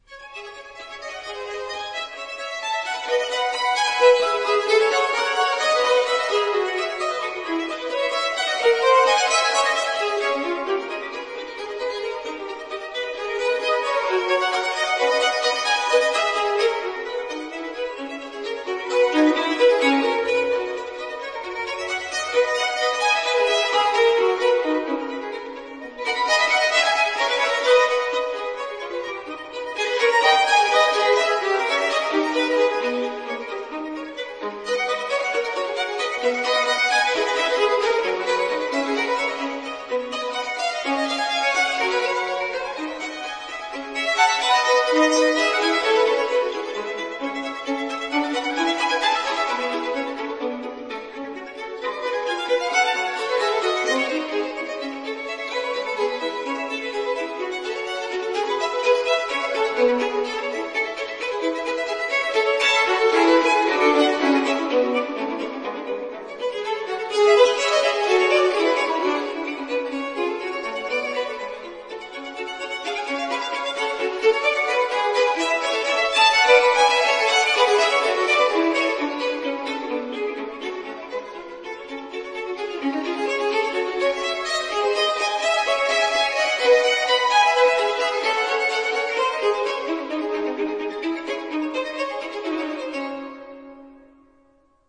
其聲響是豐富的。
試聽一是雙小提琴曲，
這些曲子好聽、美麗豐富。